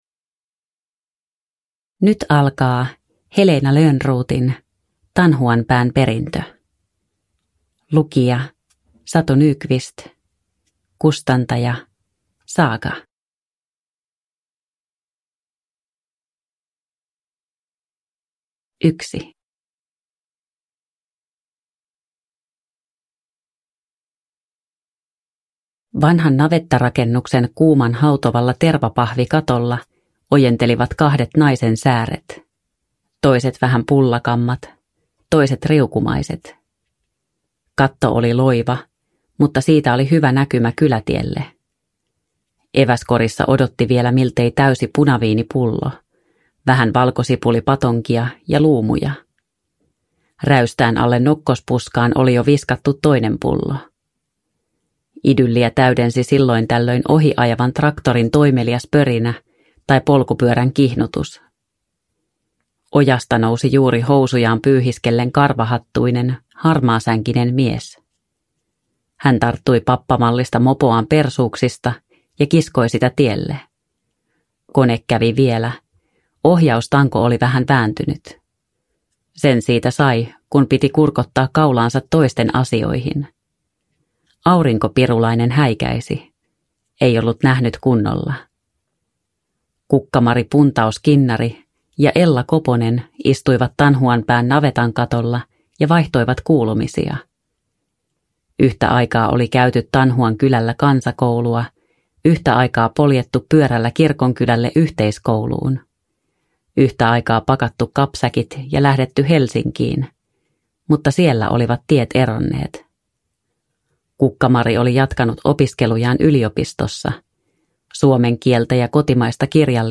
Tanhuanpään perintö (ljudbok) av Heleena Lönnroth